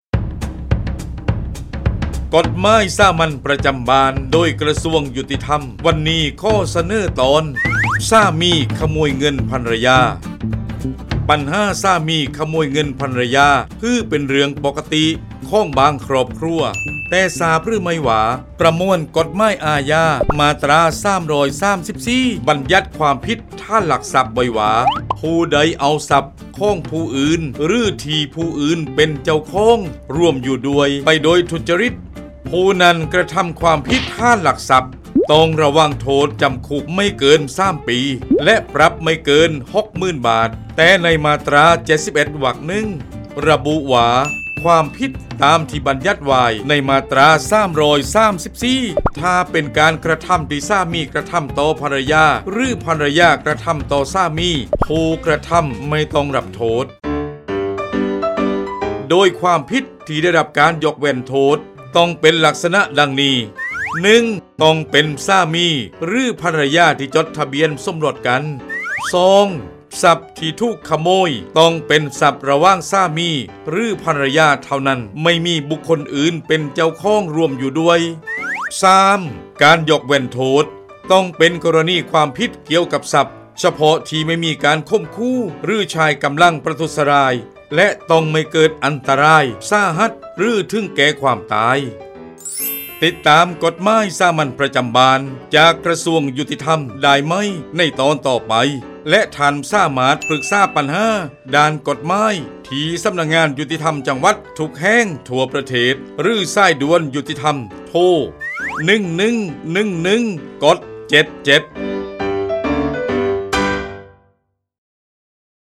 กฎหมายสามัญประจำบ้าน ฉบับภาษาท้องถิ่น ภาคใต้ ตอนสามีขโมยเงินภรรยา
ลักษณะของสื่อ :   บรรยาย, คลิปเสียง